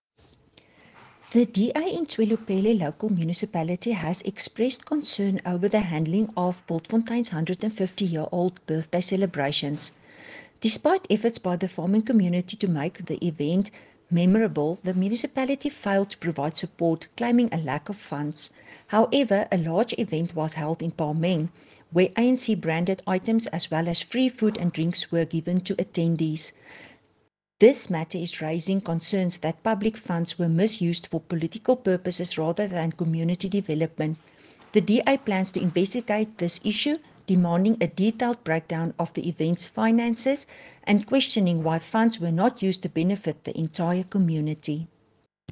Afrikaans soundbites by Cllr Estelle Pretorius and Sesotho by Jafta Mokoena MPL.